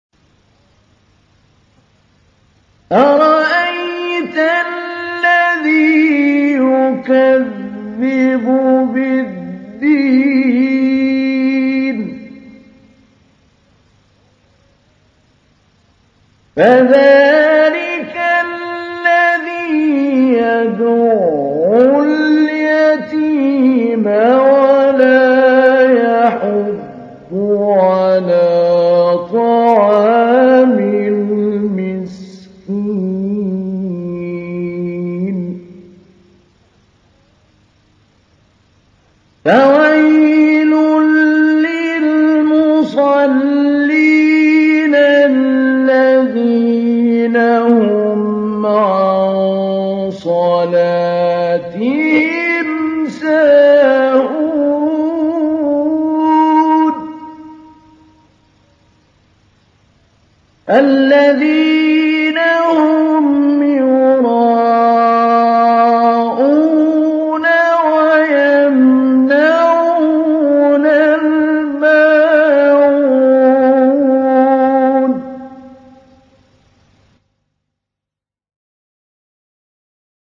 تحميل : 107. سورة الماعون / القارئ محمود علي البنا / القرآن الكريم / موقع يا حسين